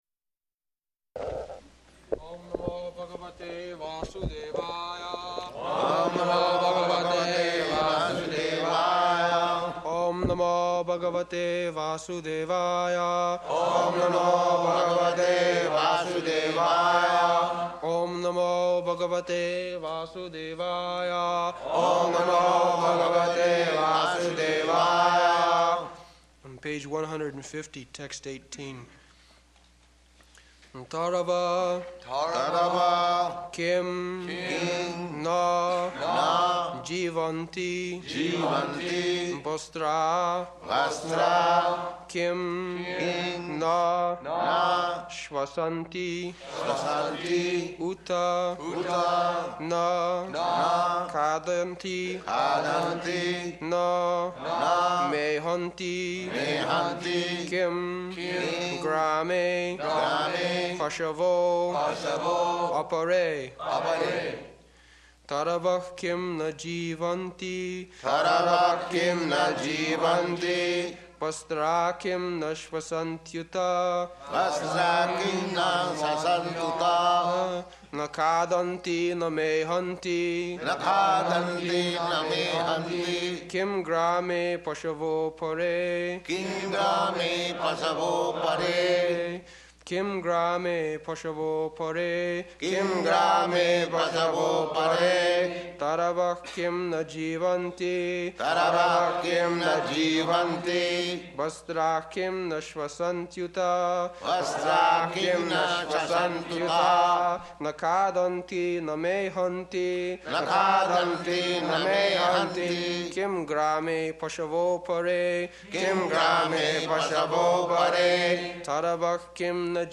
-- Type: Srimad-Bhagavatam Dated: June 13th 1972 Location: Los Angeles Audio file
[leads chanting of verse] [Prabhupāda and devotees repeat] taravaḥ kiṁ na jīvanti bhastrāḥ kiṁ na śvasanty uta na khādanti na mehanti kiṁ grāme paśavo 'pare [ SB 2.3.18 ] Prabhupāda: That's nice.